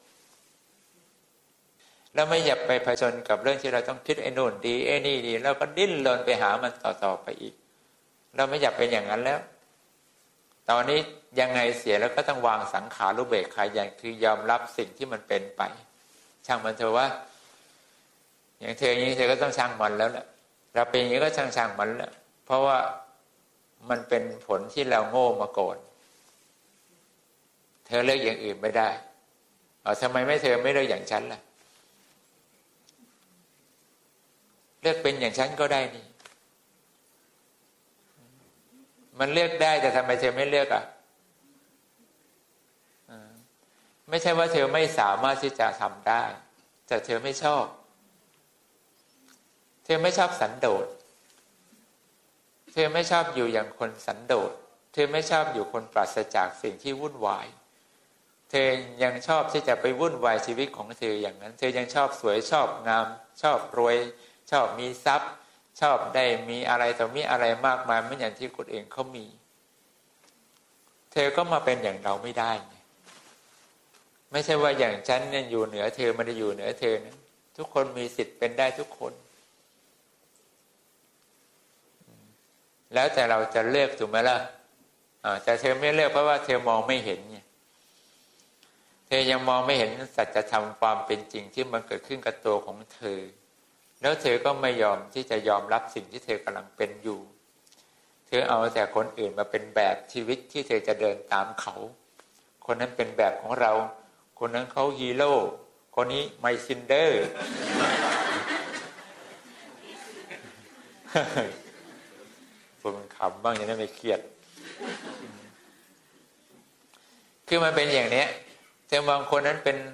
เสียงธรรม